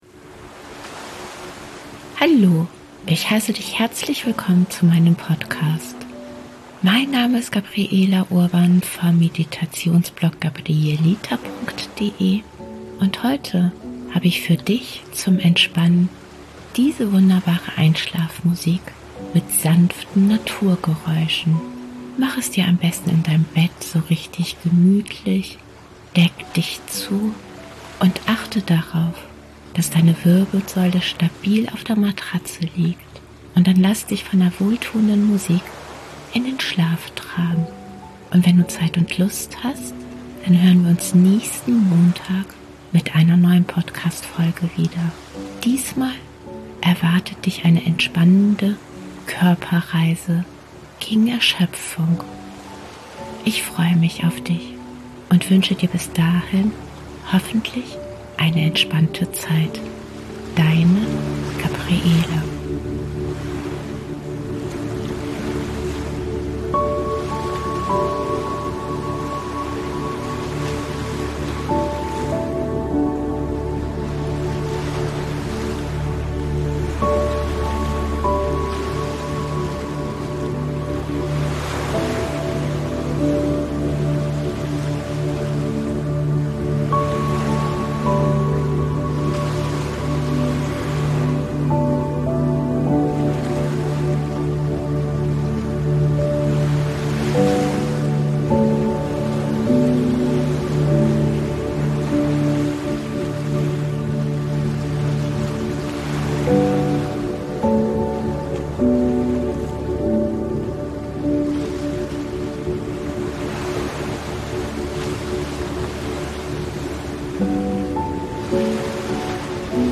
Mach es dir in deinem Bett so richtig gemütlich, achte darauf, dass deine Wirbelsäule stabil auf der Matratze aufliegt – und dann lass dich von dieser beruhigenden Einschlafmusik mit sanften Naturgeräuschen in den Schlaf begleiten.